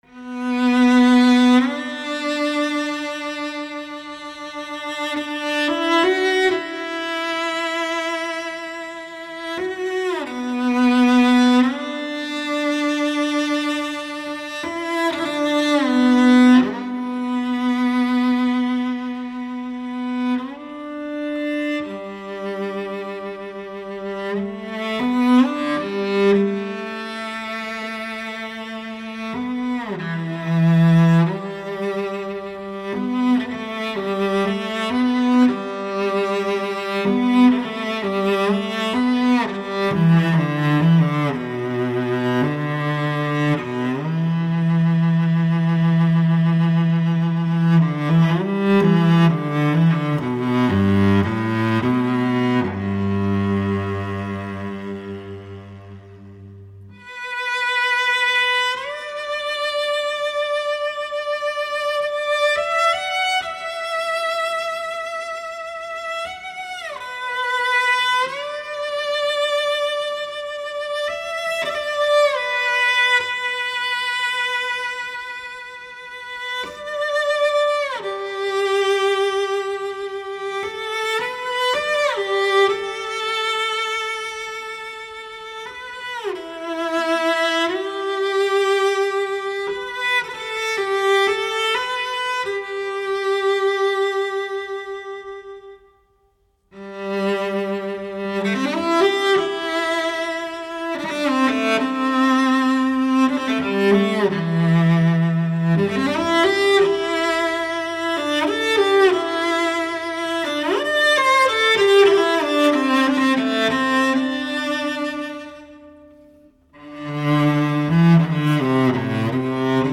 细致入微的超级录音，空灵通透，层次清晰，动态逼真传神，极具发烧品味。